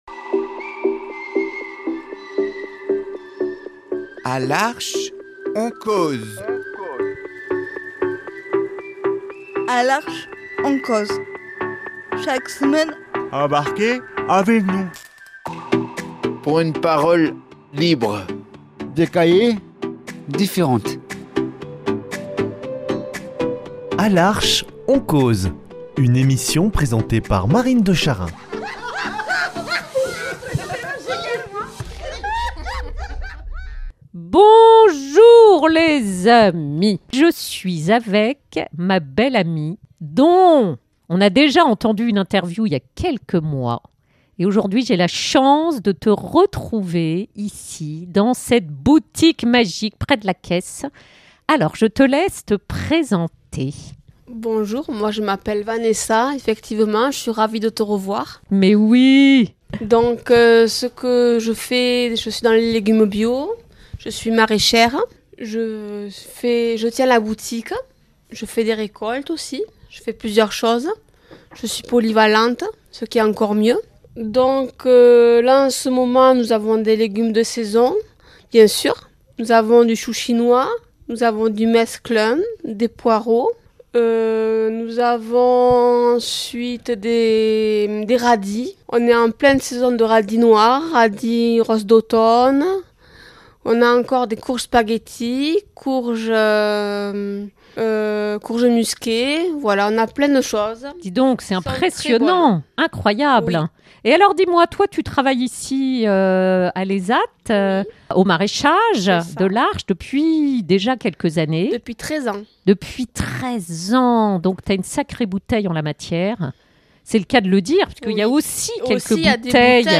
nous accueille avec ardeur et joie à la boutique pour une interview improvisée ! Chaque produit dans cette jolie boutique respire le soin et l’amour du travail bien fait de bout en bout, un vrai régal !